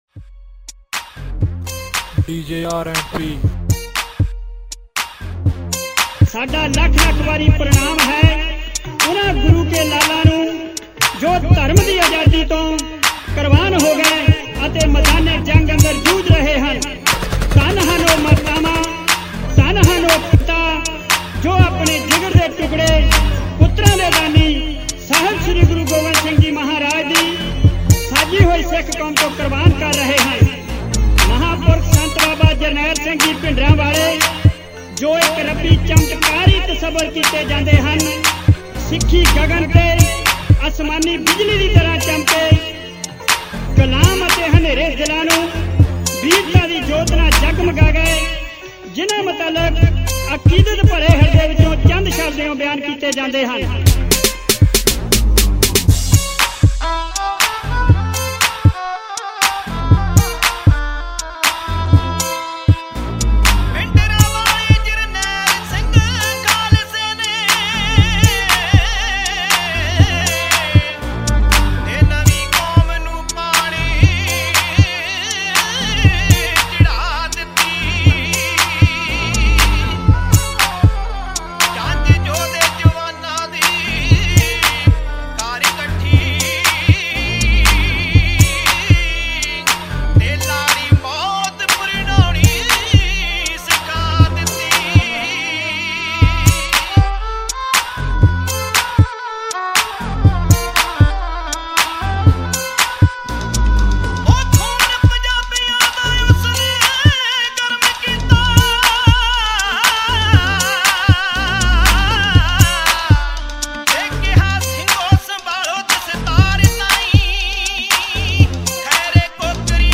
Genre: Sikh Song